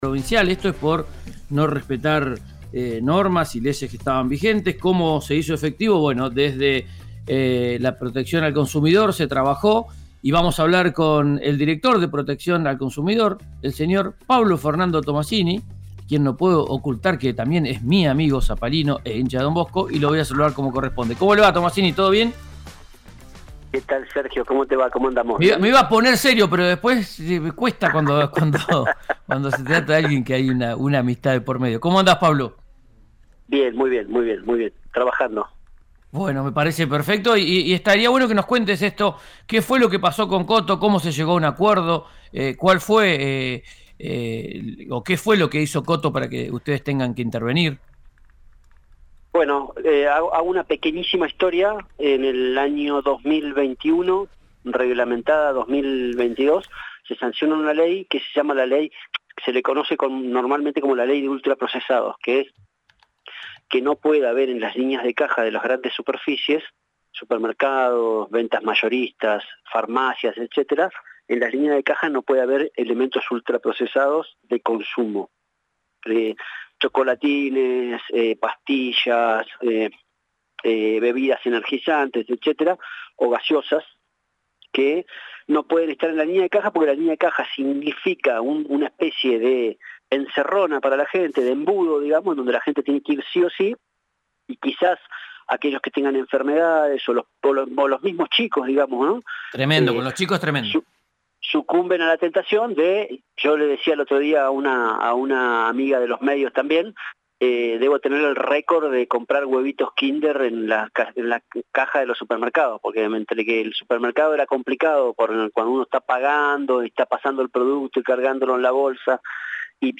Un reconocido supermercado de Neuquén, multado por infringir la ley sobre alimentos ultraprocesados, acordó realizar un aporte millonario a un programa provincial para promover el consumo responsable y saludable. Pablo Tomasini, director de Protección al Consumidor de Neuquén, contó los detalles en RÍO NEGRO RADIO.